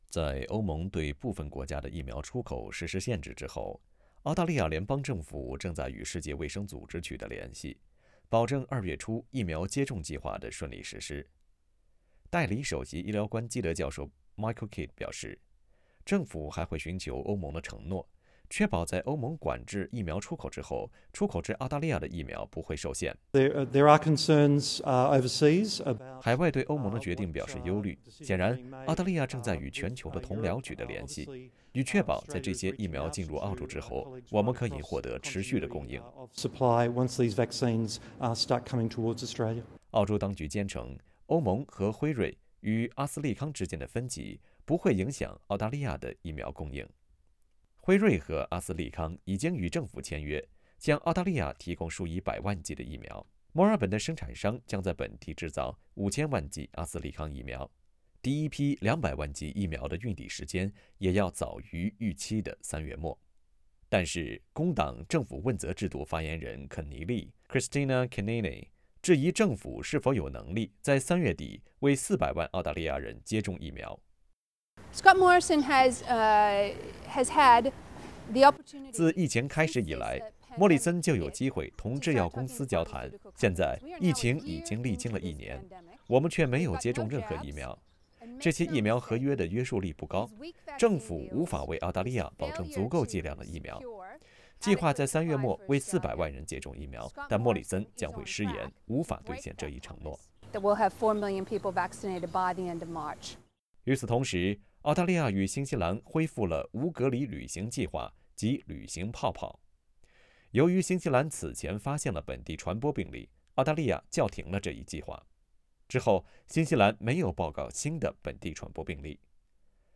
在周日，西澳珀斯一家隔离酒店的保安确诊感染新冠，大珀斯地区从周日下午6点开始封城。（点击图片，收听采访）